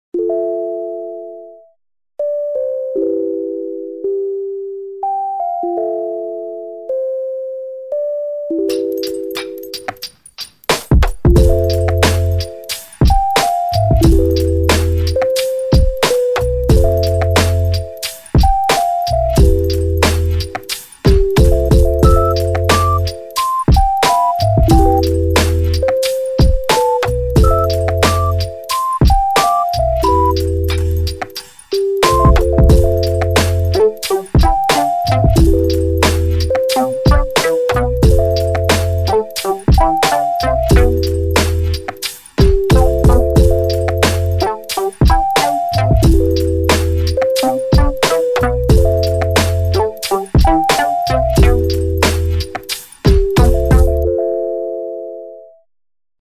The sine wave: so simple and pure.
audio_sinebeauty.mp3